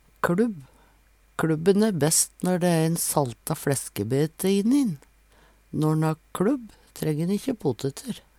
kLubb - Numedalsmål (en-US)